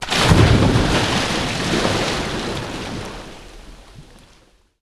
SPLASH_Deep_01_mono.wav